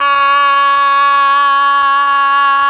RED.OBOE  19.wav